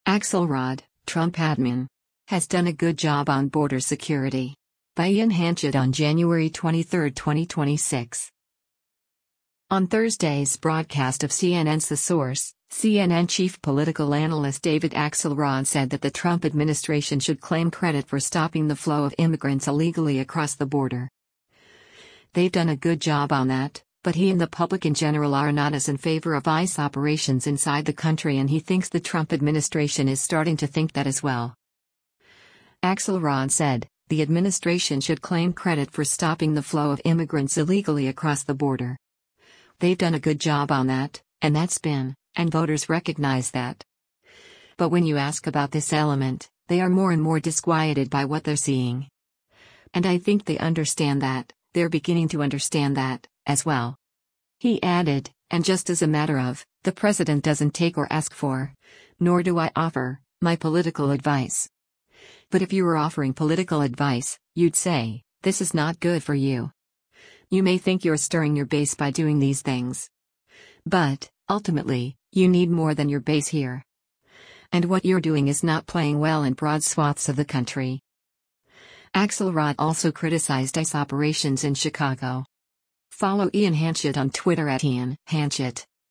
On Thursday’s broadcast of CNN’s “The Source,” CNN Chief Political Analyst David Axelrod said that the Trump administration “should claim credit for stopping the flow of immigrants illegally across the border. They’ve done a good job on that,” but he and the public in general are not as in favor of ICE operations inside the country and he thinks the Trump administration is starting to think that as well.